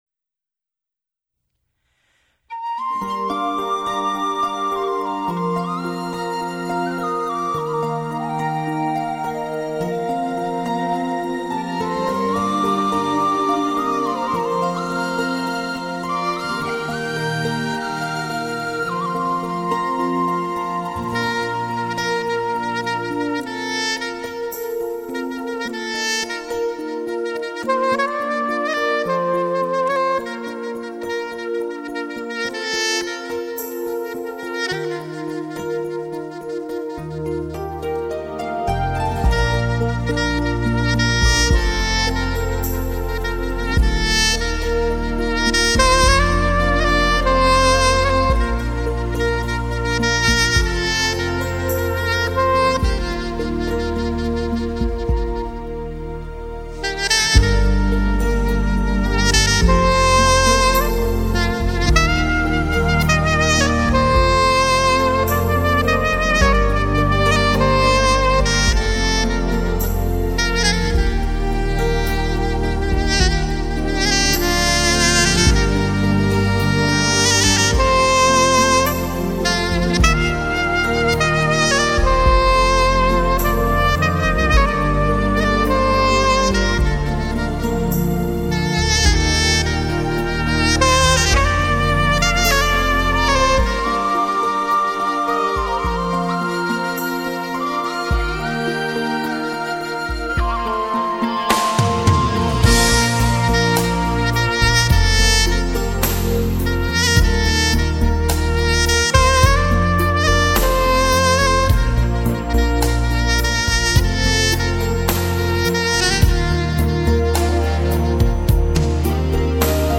萨克斯曲